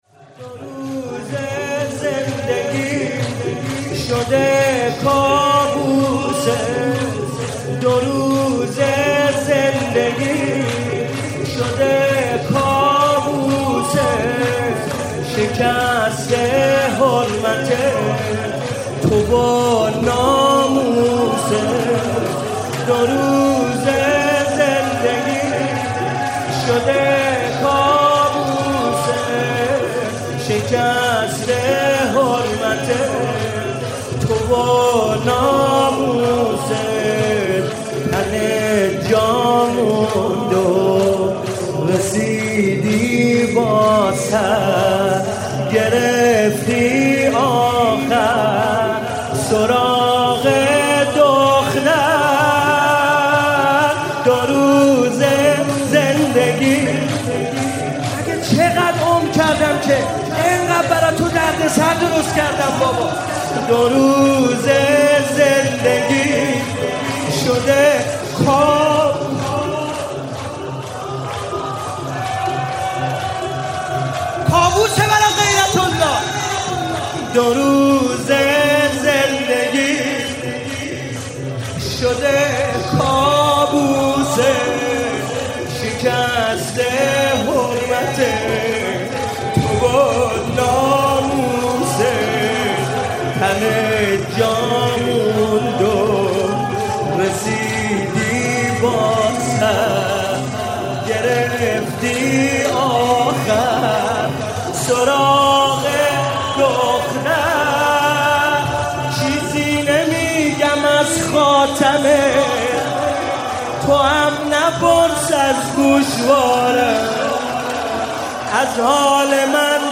محرم 97 شب سوم
شور - دو روز زندگی